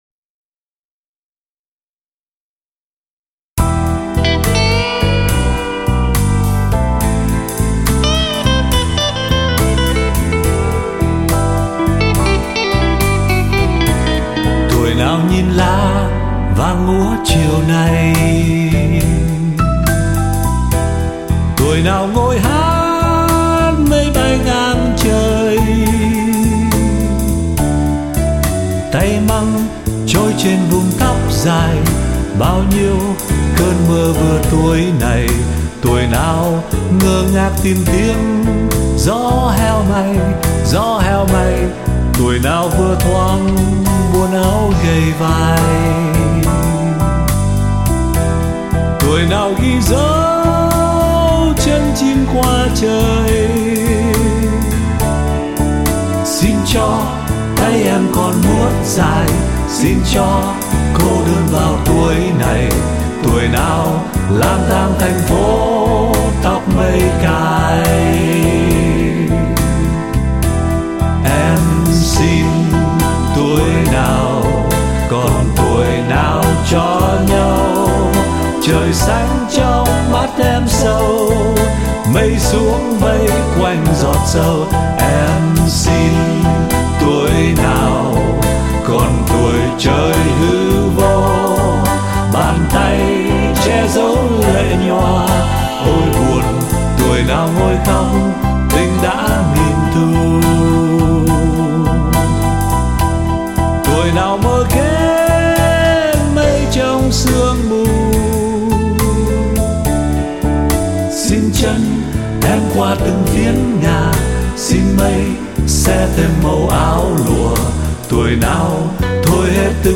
Giọng hát đam mê trực tiếp đi vào lòng người nghe.